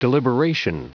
Prononciation du mot deliberation en anglais (fichier audio)
Prononciation du mot : deliberation